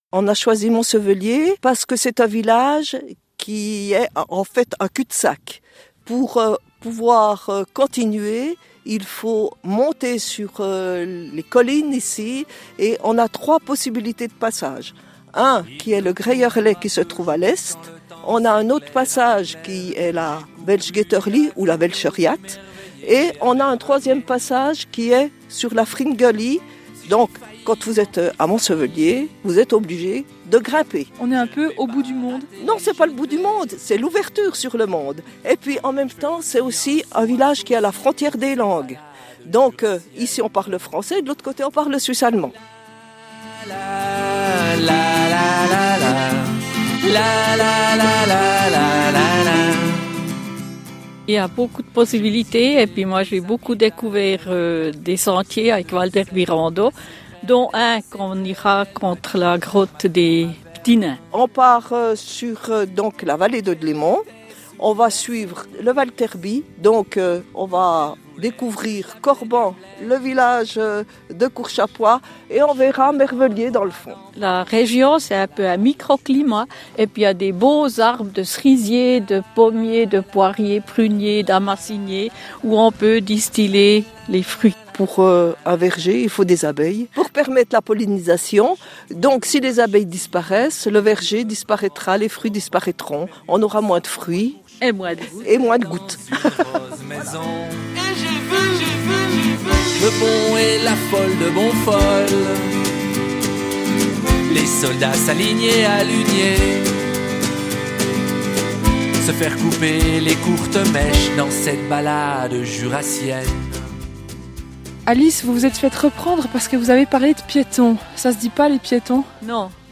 La balade jurassienne se pratique entre amies ce mercredi sur RFJ.